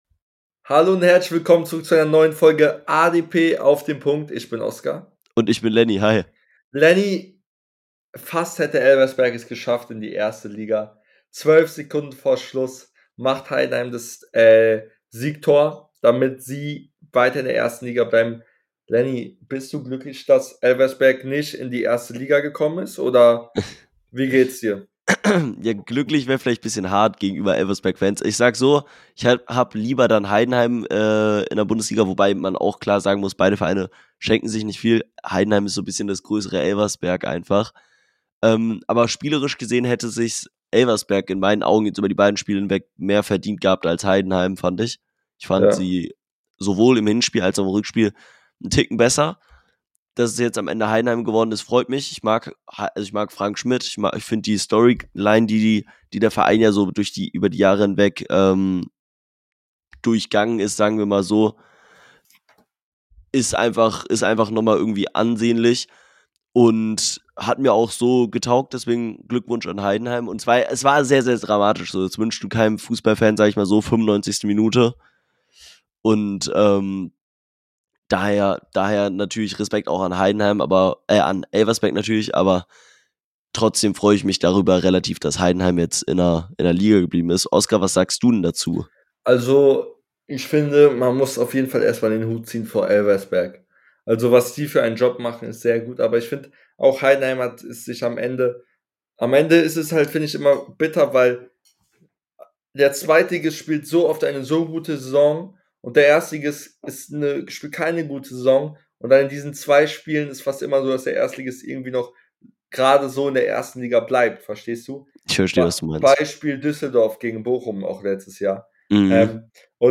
Die beiden Hosts sprechen über Werders Umbruch , die Relegation , blicken auf das Champions league Finale und vieles mehr